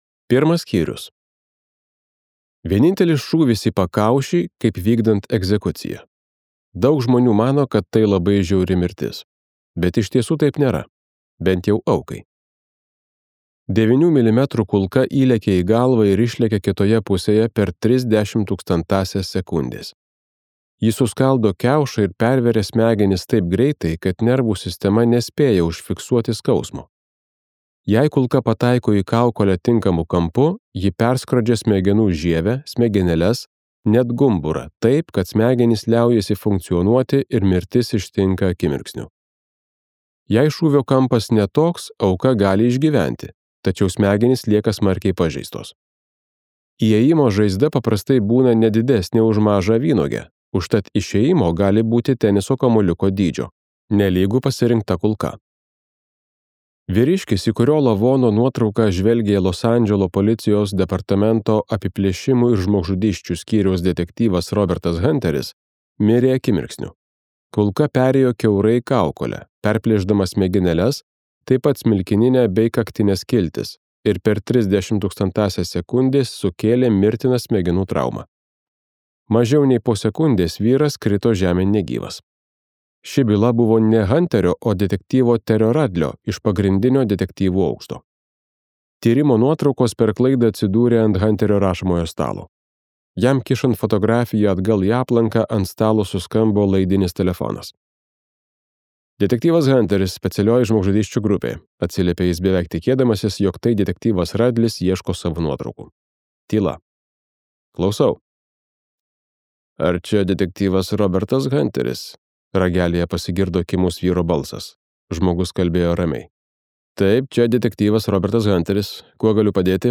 Chris Carter audioknyga „Vieną po kito“ – dar vienas šio rašytojo trileris, kaustantis vaizdingomis ir žiauriomis scenomis. Šį kartą žudikas savo nešvarius darbelius transliuoja tiesiogiai internetu.